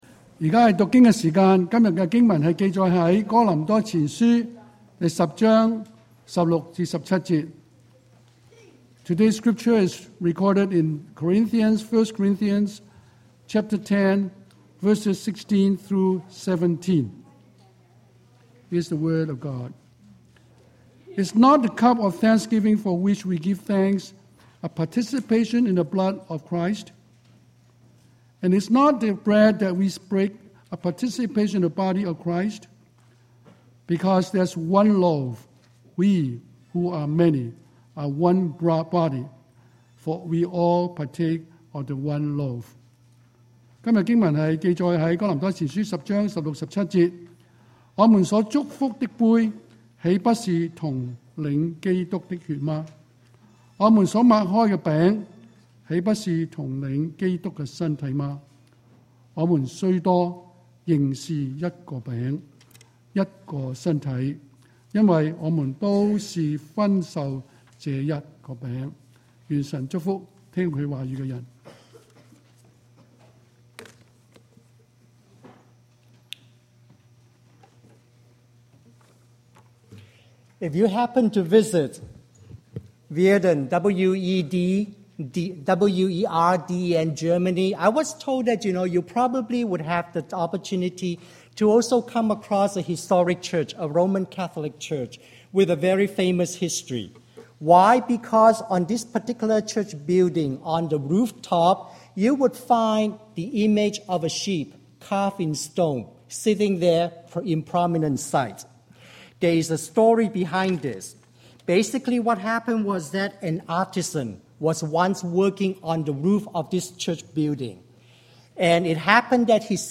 Communion and Commitment: June 7, 2009 Sermon